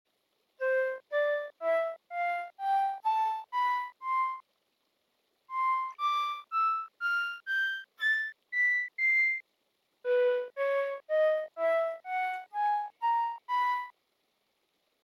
Basic_Flute_Sound.mp3